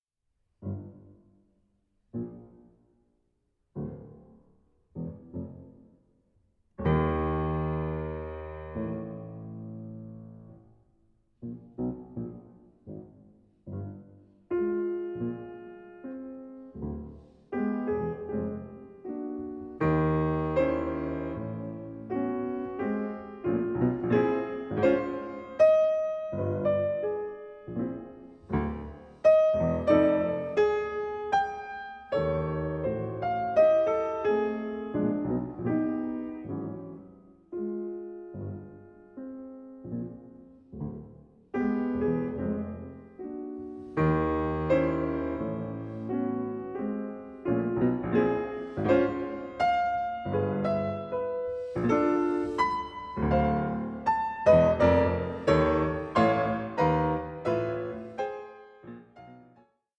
Andante con moto 3:38